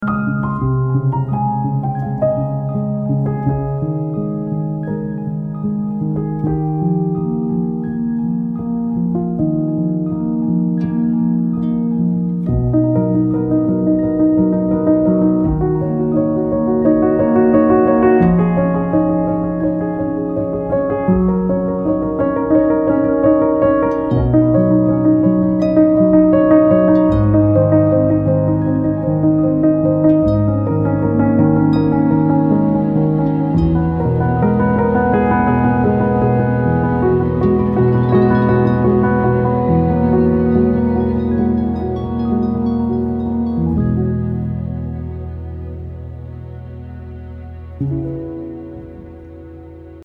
Post Classical >